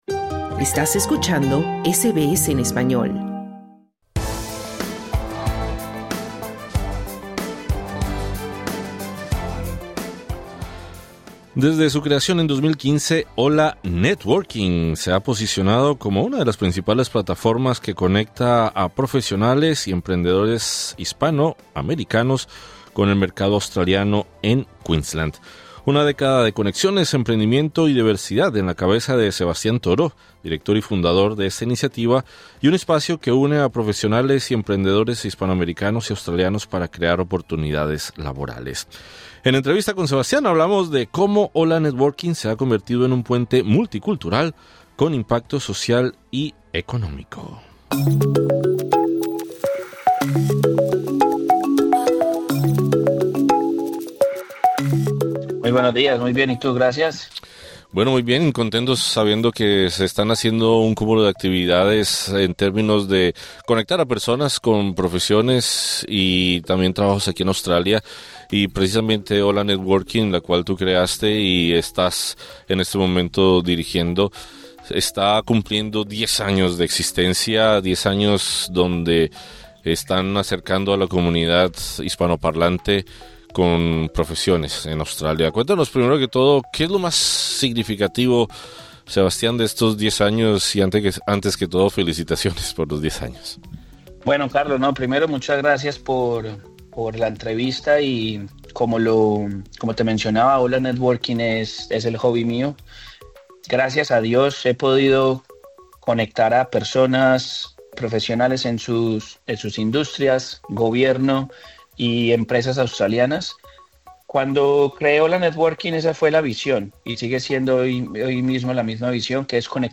En este episodio entrevistamos